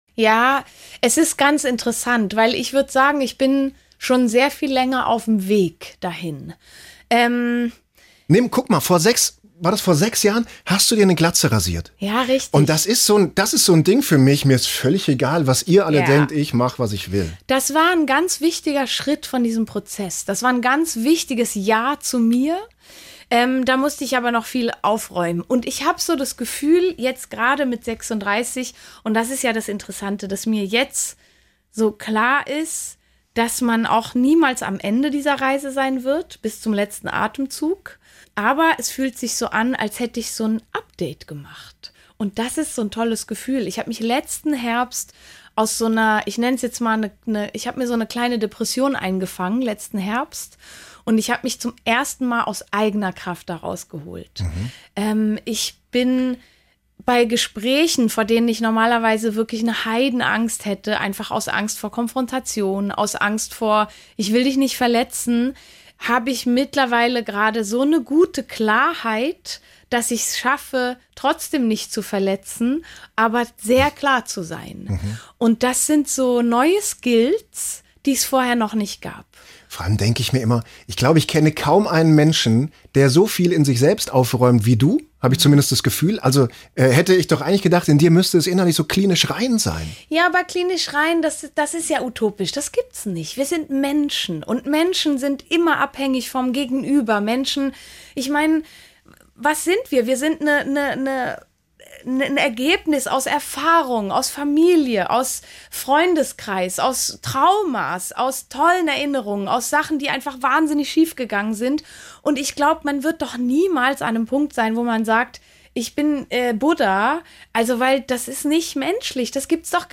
Stefanie Heinzmann über ihre innere Stärke im SWR3 Interview